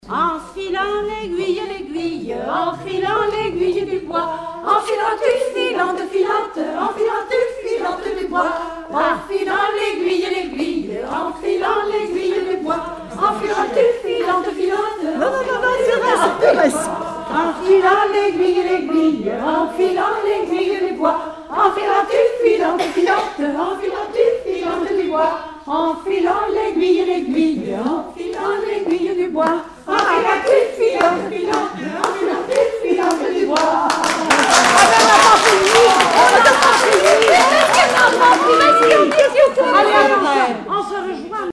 Enfantines - rondes et jeux
Regroupement de chanteurs du canton
Pièce musicale inédite